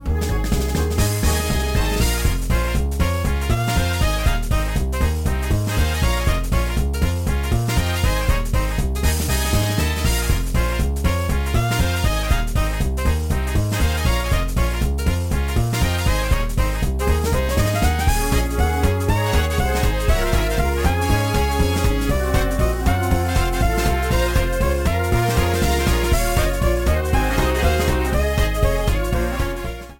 big-band arrangement